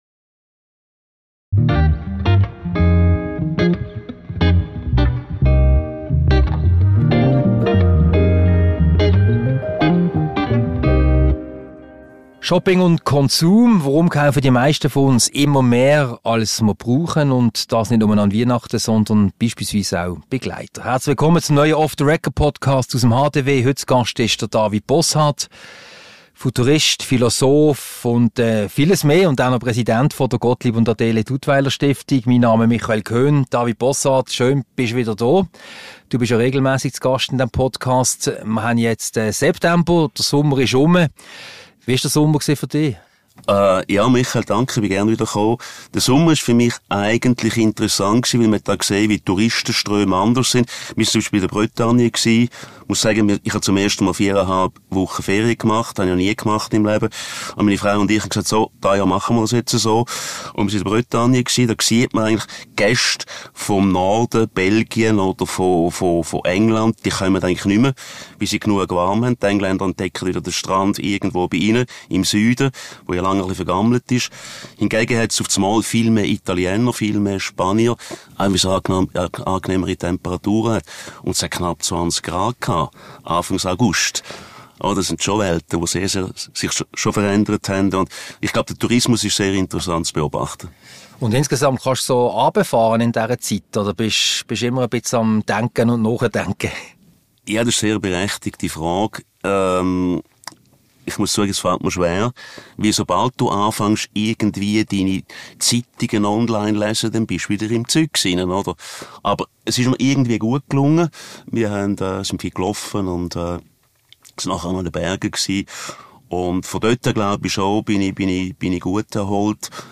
Ein Gespräch über Shopping und Konsum.